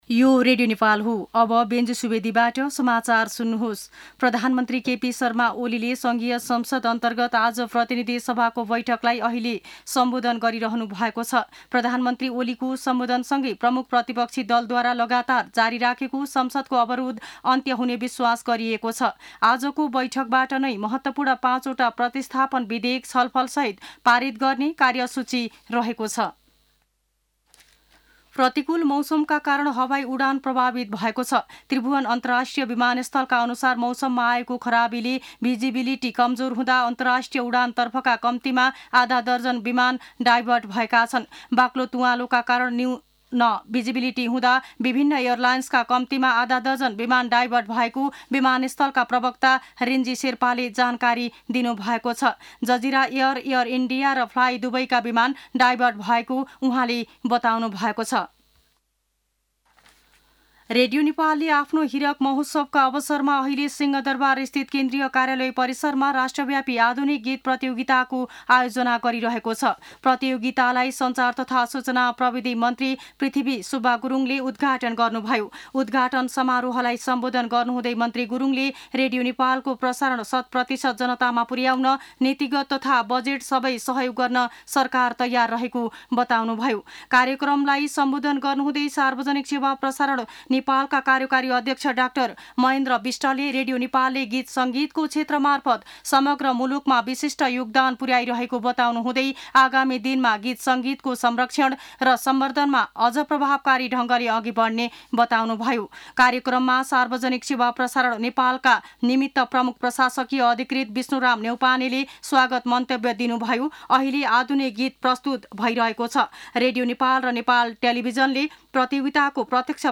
मध्यान्ह १२ बजेको नेपाली समाचार : १८ चैत , २०८१